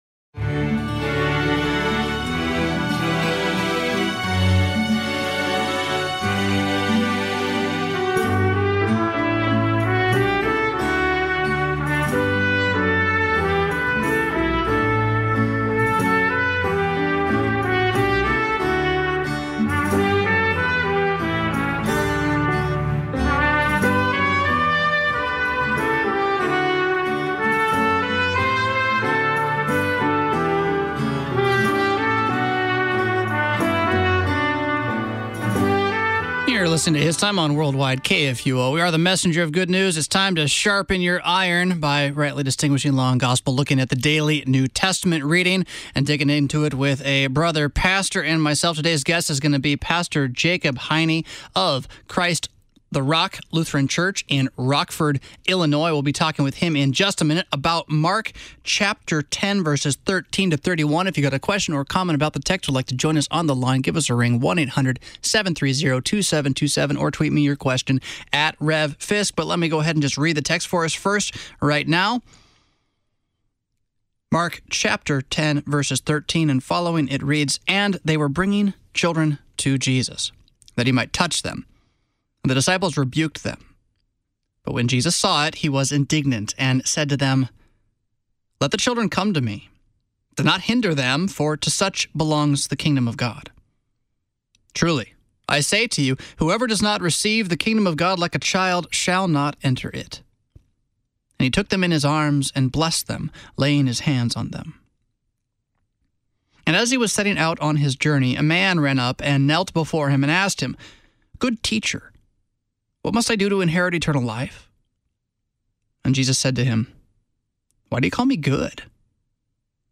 Morning Prayer Sermonette